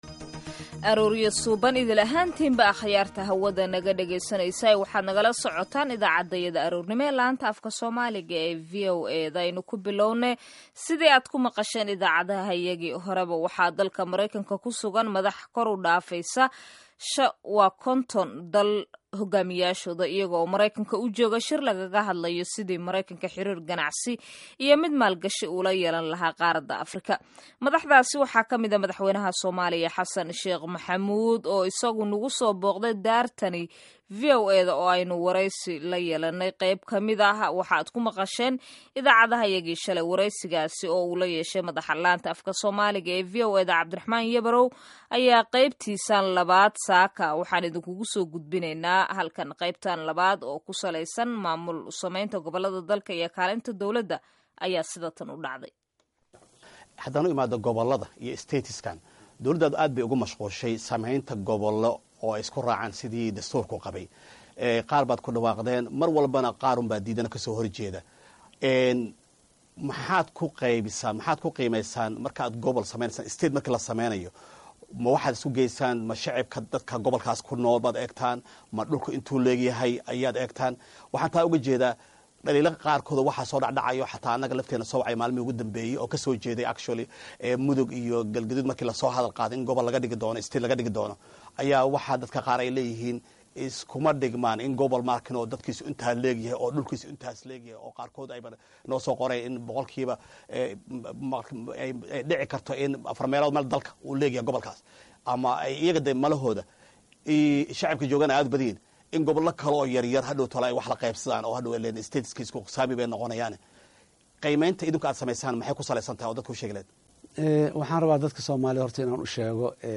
Wareysiga Madaxweyne Xasan Sheekh